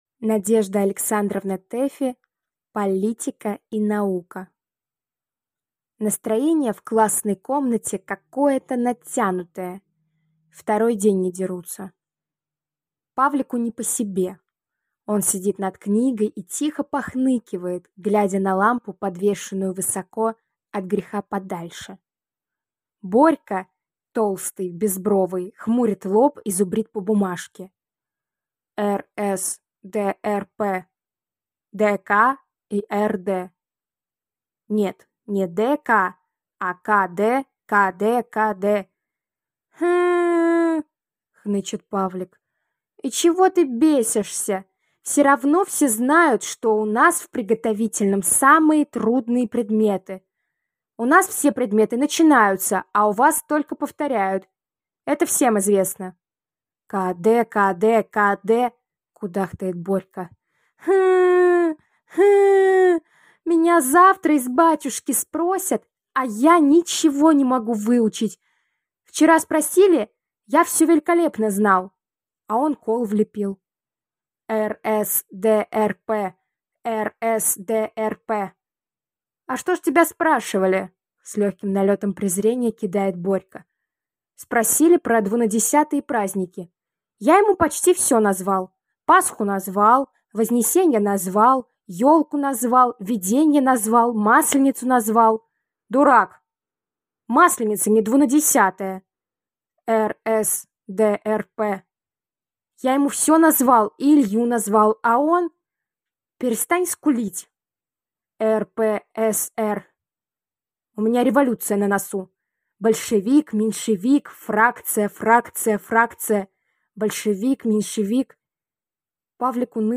Аудиокнига Политика и наука | Библиотека аудиокниг